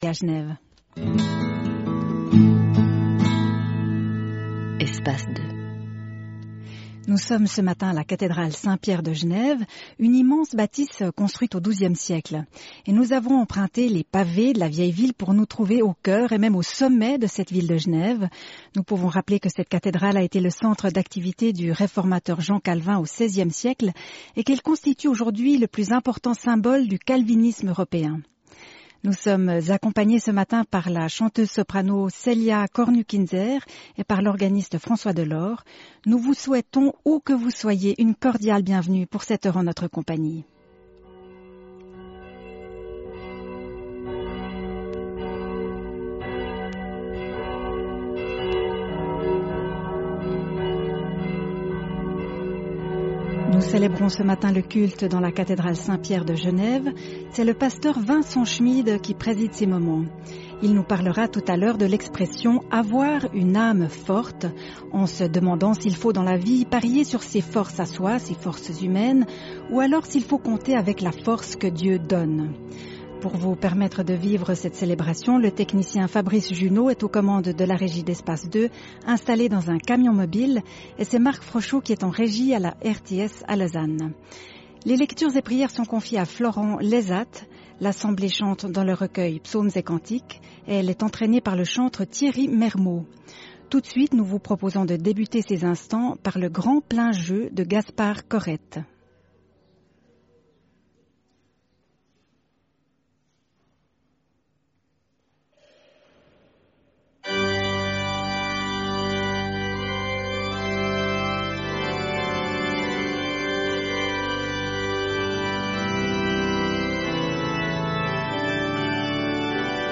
Prédications dans le même lieu